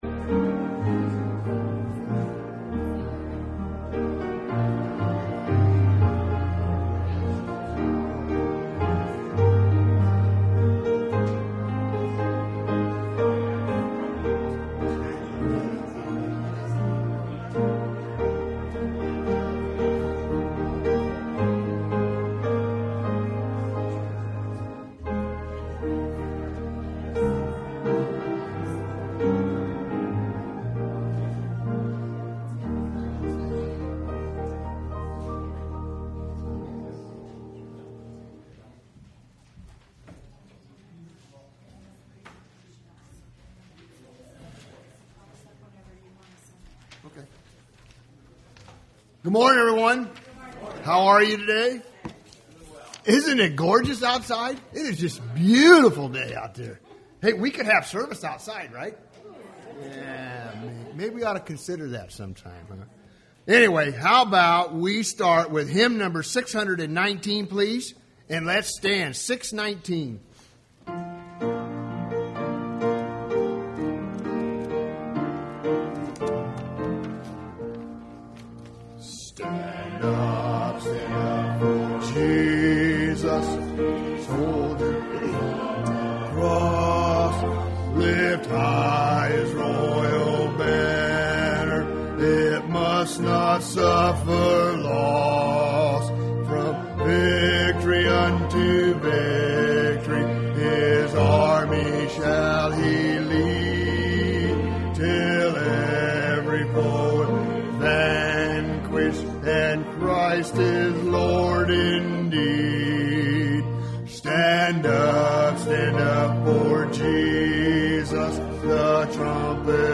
Service Type: Sunday Morning Service Topics: Revival